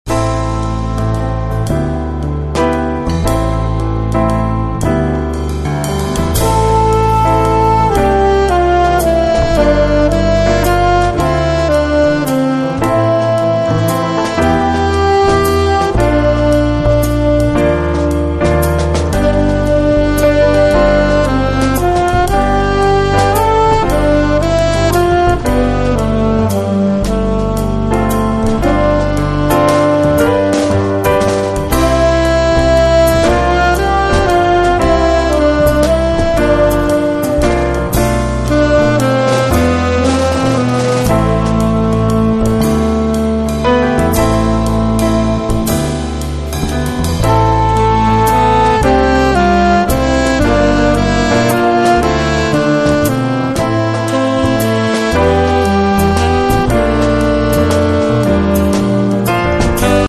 sax contralto
sax tenore
piano
contrabbasso e basso elettrico
batteria
una sorta di fusion acustica